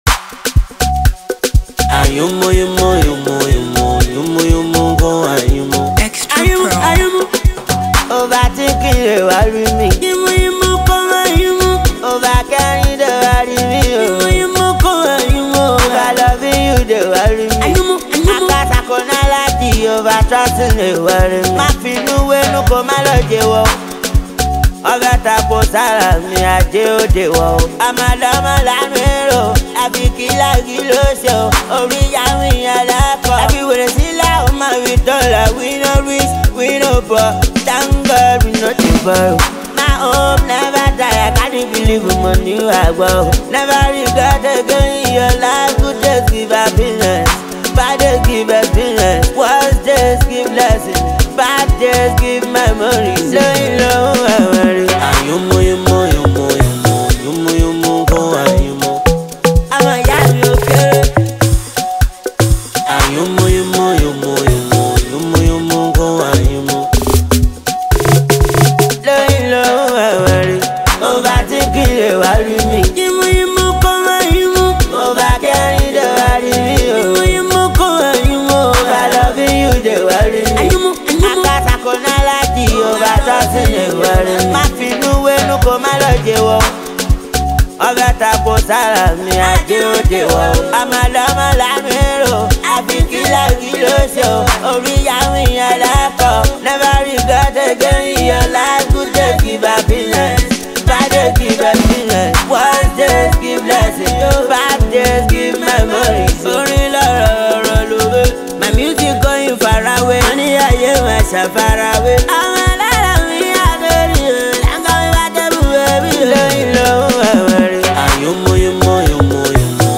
Super talented Nigerian street-hop music virtuoso
pulsating track